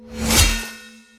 melee-hit-9.ogg